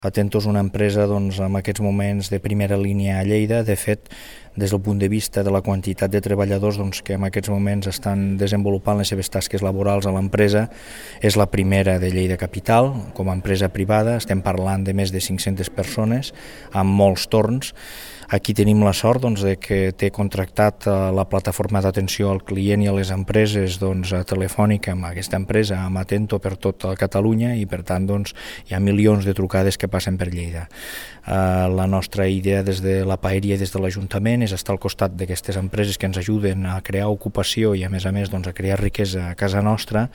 Tall de veu del tinent d'alcalde Josep Presseguer sobre la visita a l'empresa Atento
tall-de-veu-del-tinent-dalcalde-josep-presseguer-sobre-la-visita-a-lempresa-atento